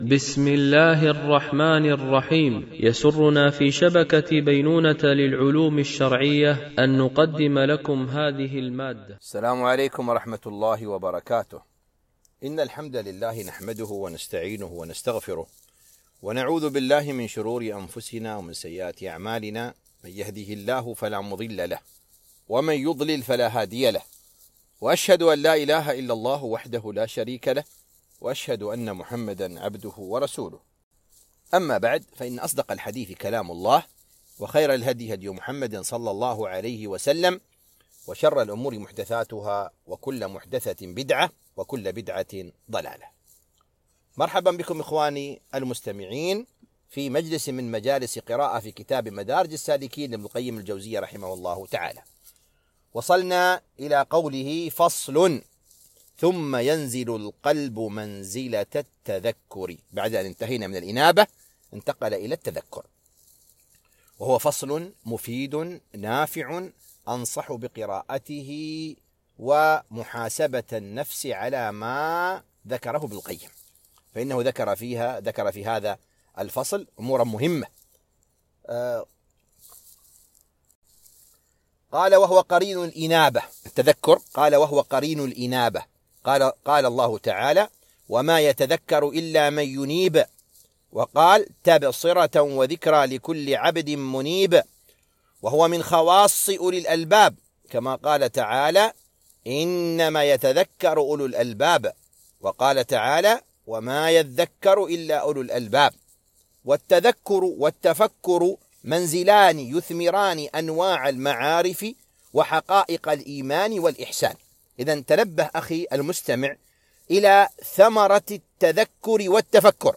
قراءة من كتاب مدارج السالكين - الدرس 47